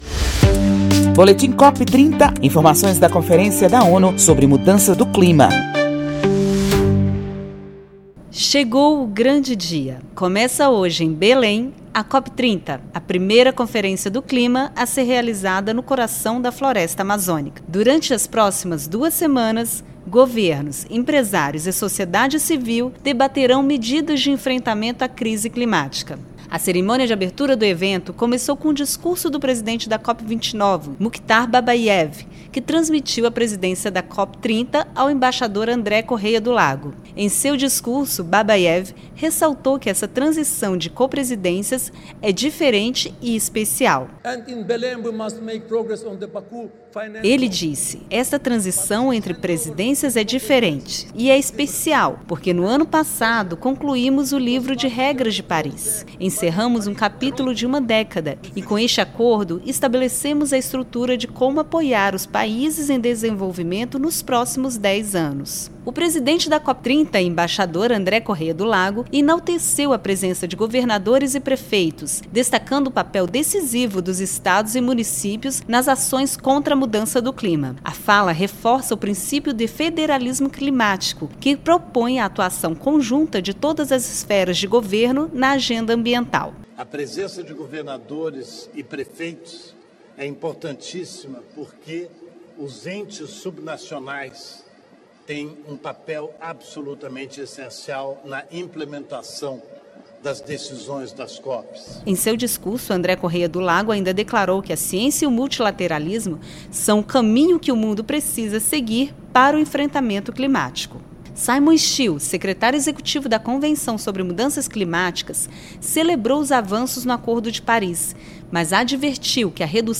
Na quarta carta à comunidade internacional, a Presidência brasileira da COP30 convoca o mundo para conter o avanço da crise climática, com foco em implementar as metas do Acordo de Paris e 6 eixos temáticos, visando limitar o aquecimento a 1,5°C. Ouça reportagem e saiba mais.